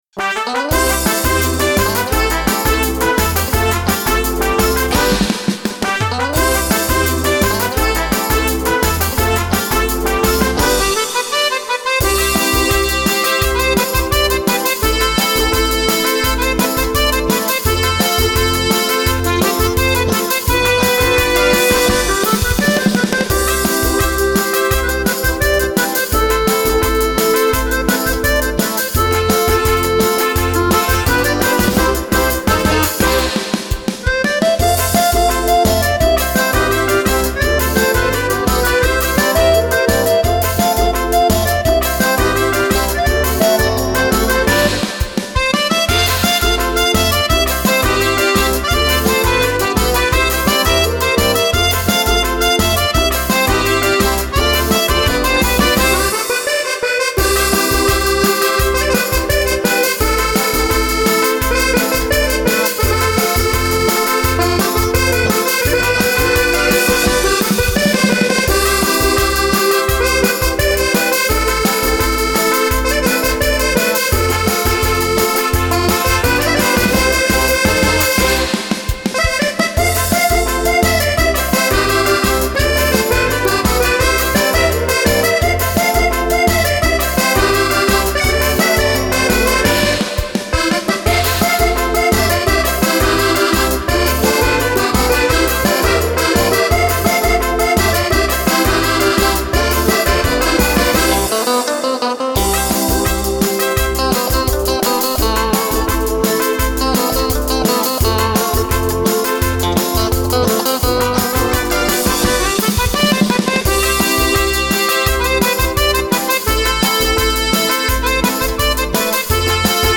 version accordéon